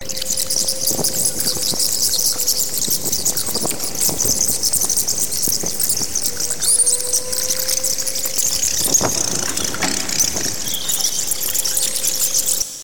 Звуки мышей
Летучие мыши под крышей